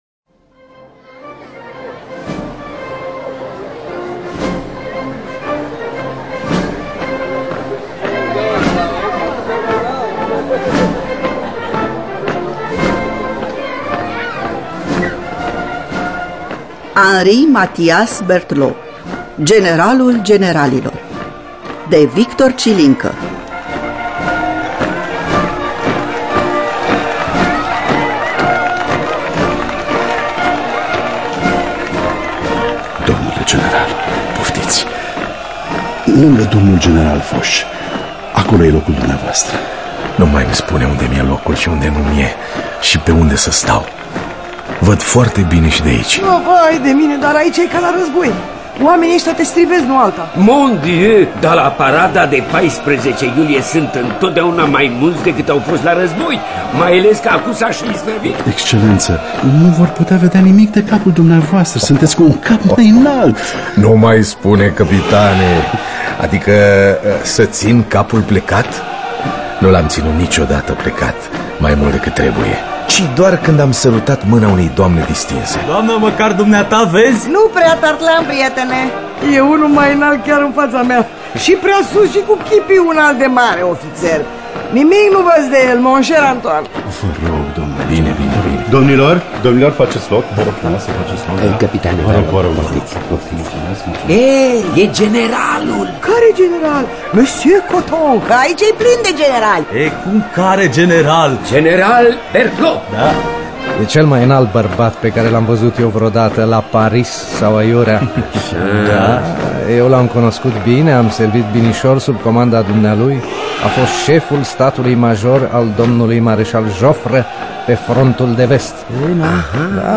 Henri Mathias Berthelot, generalul generalilor de Victor Cilincă – Teatru Radiofonic Online